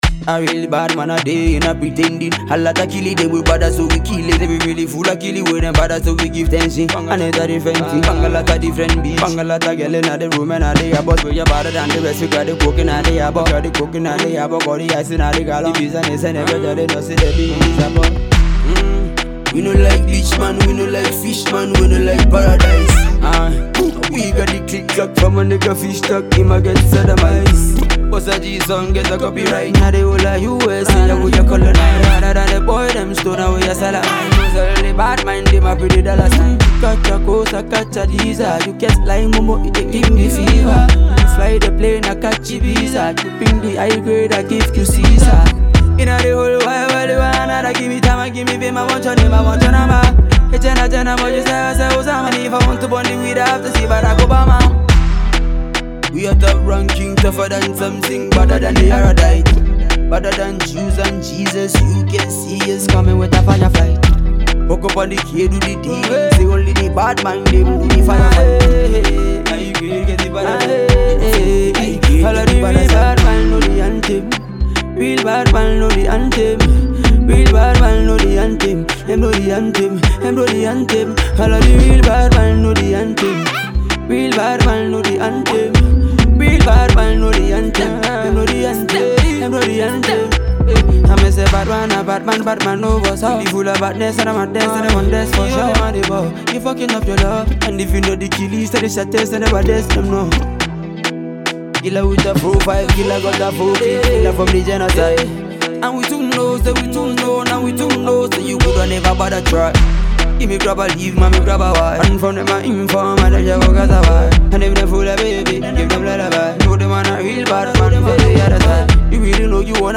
a Ghanaian reggae dancehall act
caribbean song
dope studio reocrd